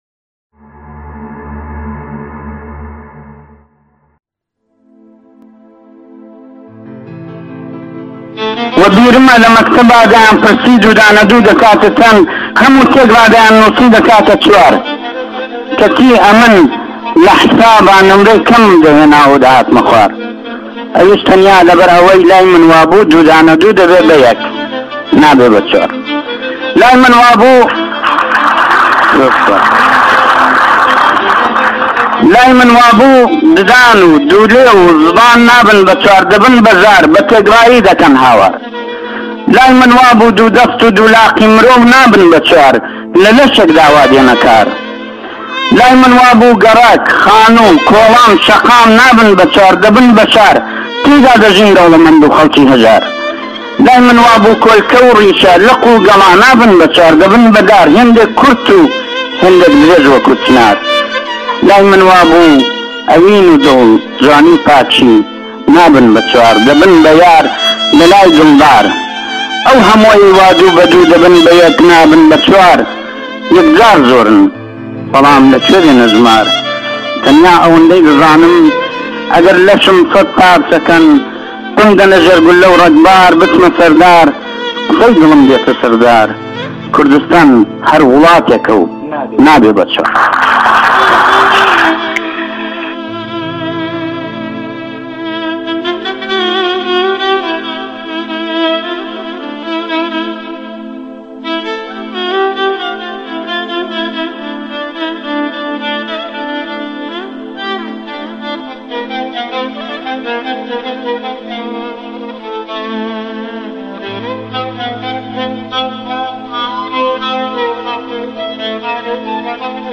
خطابي 2+2=1